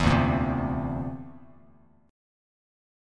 D7sus2#5 chord